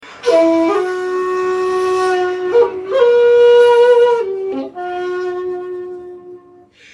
Shakuhachi 56